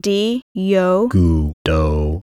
Both grammars involved sequences of syllables spoken by a female and male speaker - A syllables were spoken by the female and were ba, di, yo, tu, la, mi, no, or wu; B syllables were spoken by the male and were pa, li, mo, nu, ka, bi, do, or gu.
di-yo-gu-do.wav